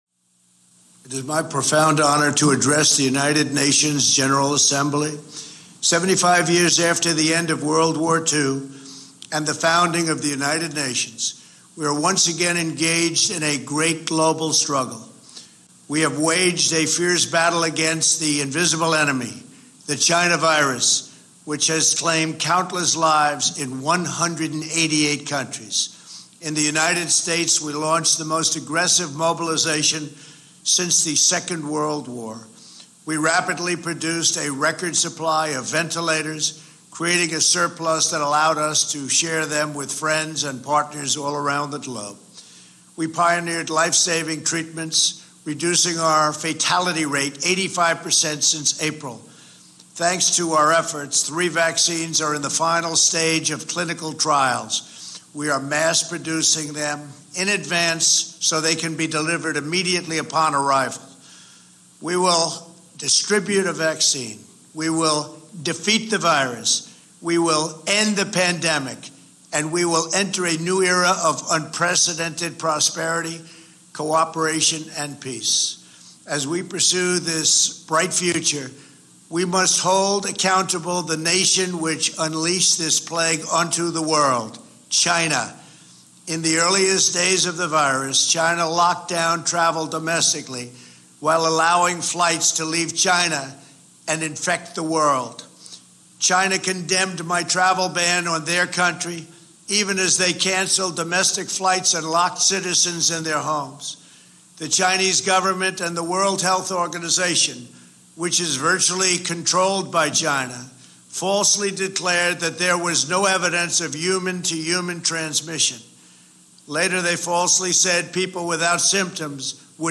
Prerecorded Address to the 75th United Nations General Assembly